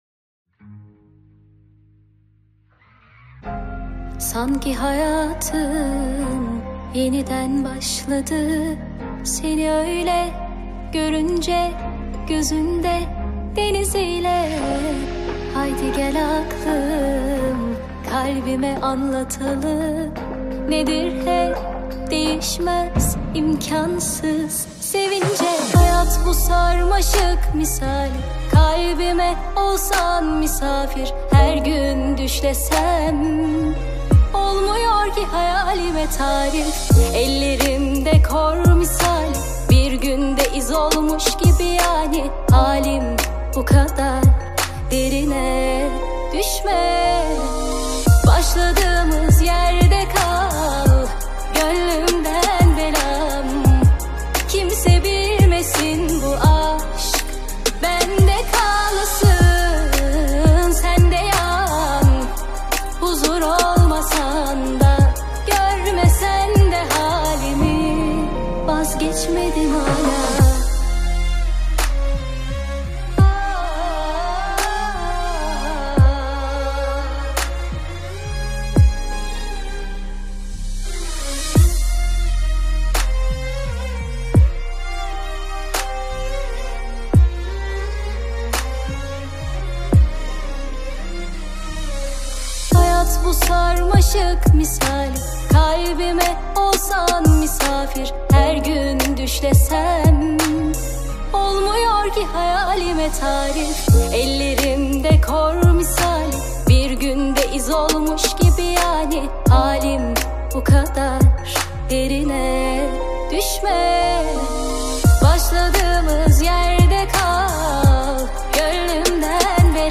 Турецкая музыка